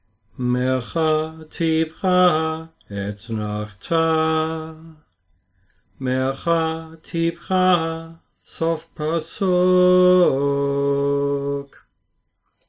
First, let us sing the sequence of
six trops.